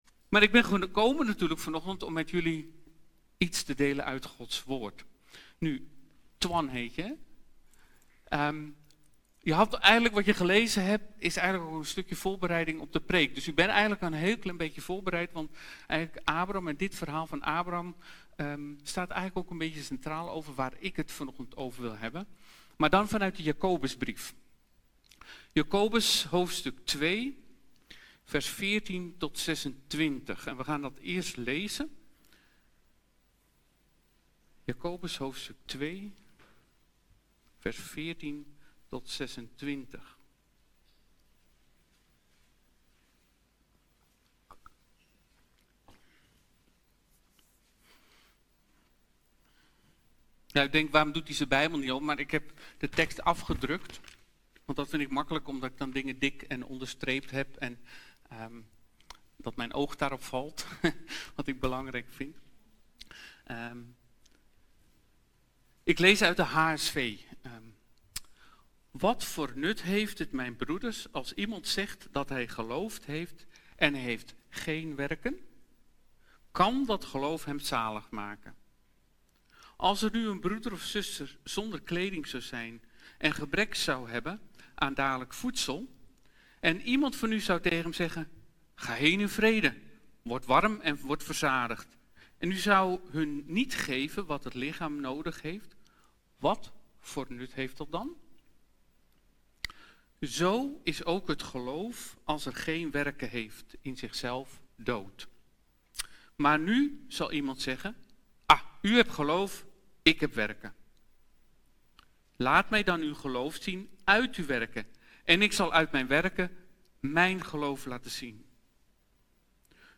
Geplaatst in Preken